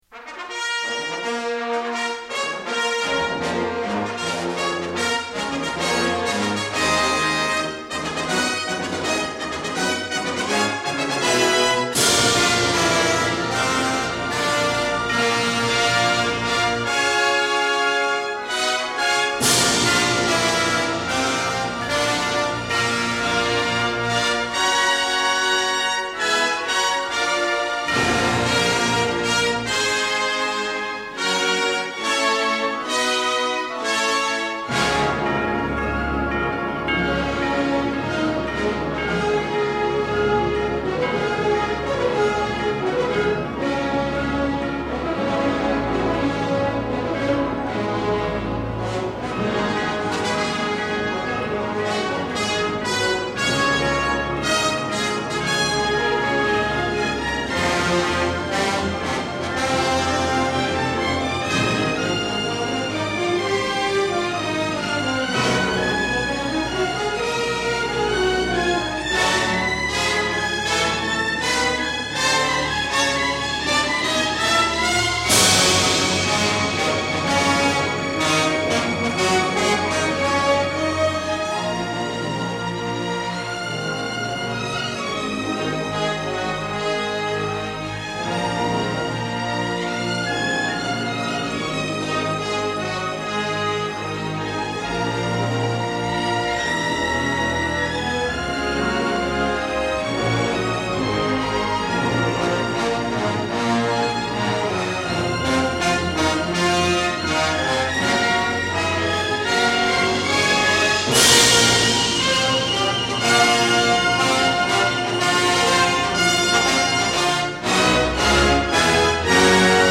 电影音乐采用多主题的交响音乐，其中竞技主题气势如虹、爱情主题炽烈而饱含伤痛。